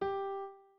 01_院长房间_钢琴_10.wav